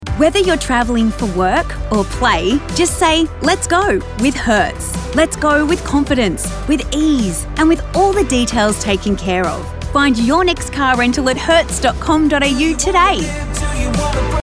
Female
English (Australian)
Adult (30-50)
A friendly, warm and empathic voice with a bubbly and catchy energy.
Television Spots
Travel, Fun